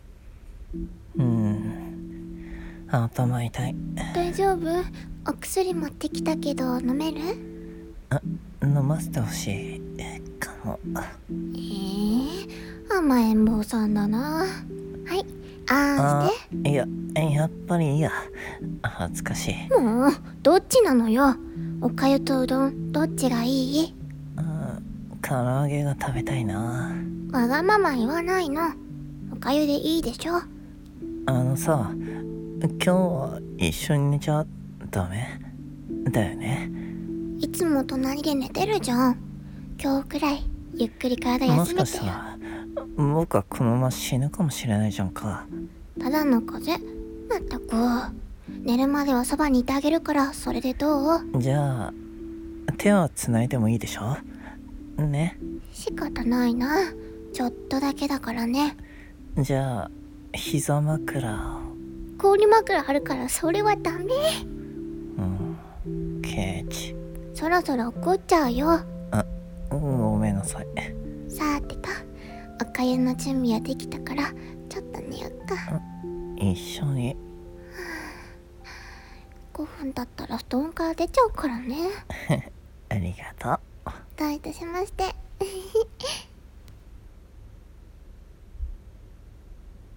〖風邪引き彼氏のわがままタイム〗 【声劇】【二人用】【純愛】【恋愛】